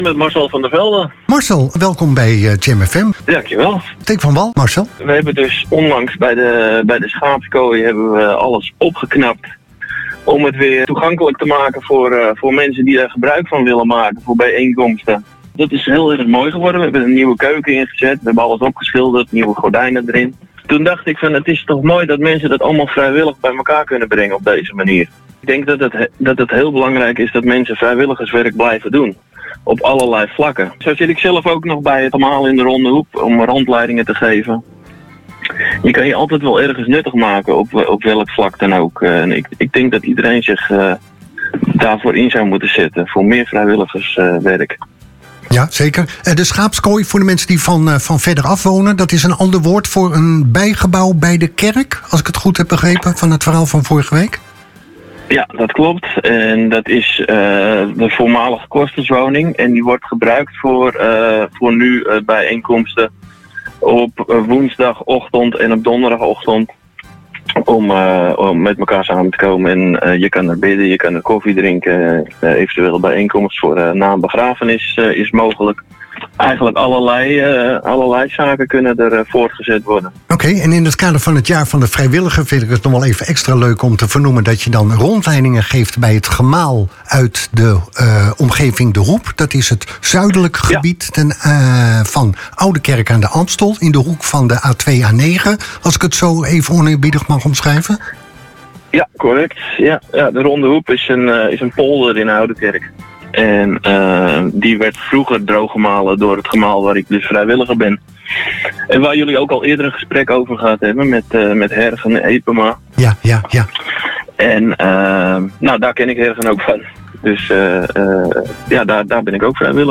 Streektelefoon